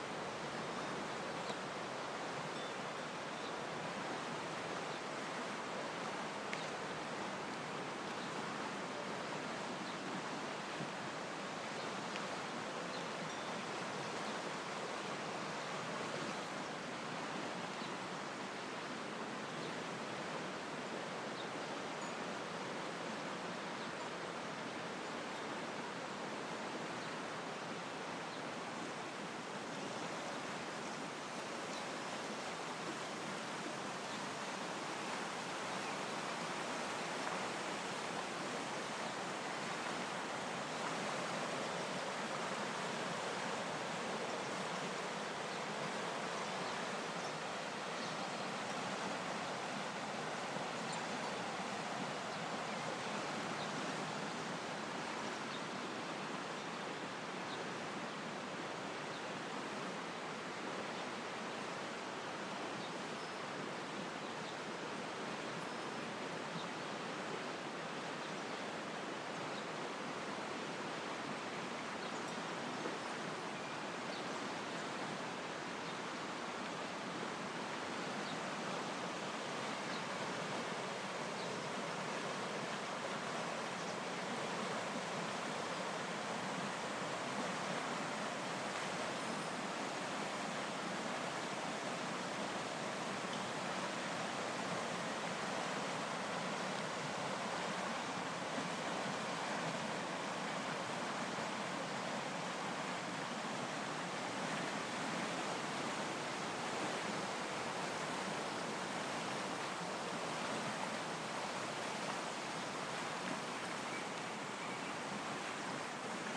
Sur les berges de la Sarine. Fribourg, Suisse.